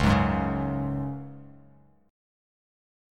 C#sus4 chord